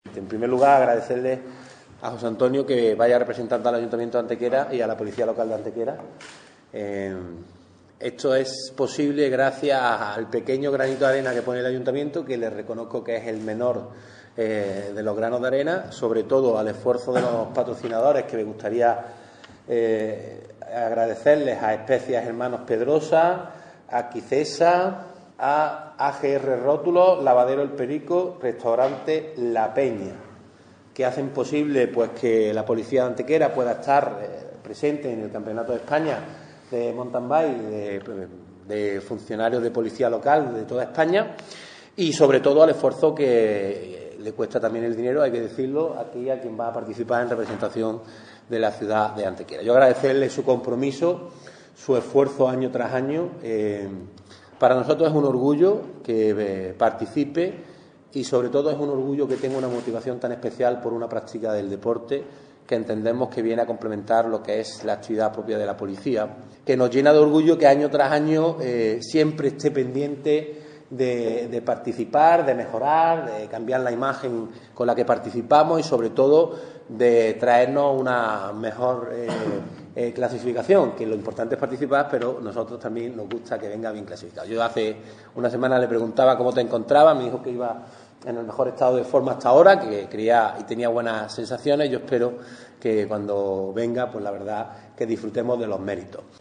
El teniente de alcalde delegado de Seguridad y Tráfico, Ángel González, ha anunciado hoy en rueda de prensa la participación de Antequera en el inminente Campeonato de España de Mountain Bike para Policías Locales, prueba deportiva que se desarrollará en la localidad gallega de Vigo este sábado 28 de septiembre.
Cortes de voz A. González 755.12 kb Formato: mp3